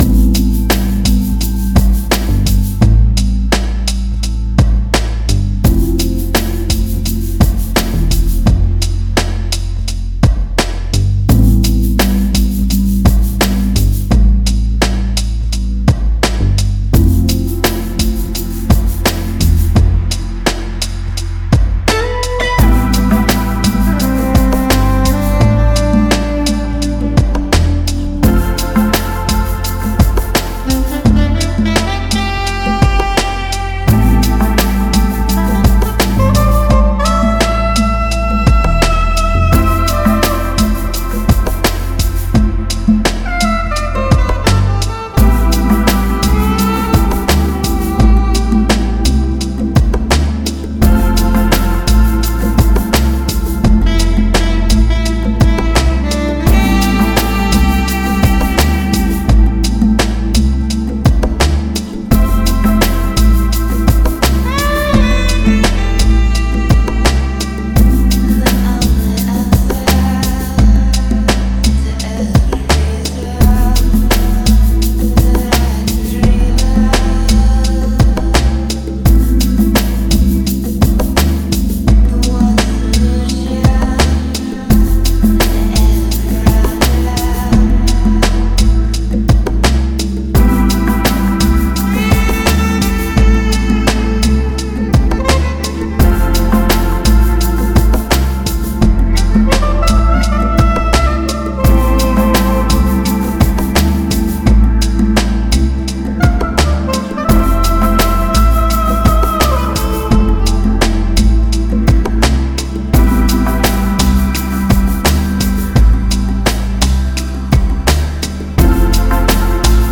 FLAC Жанр: Balearic, Downtempo Издание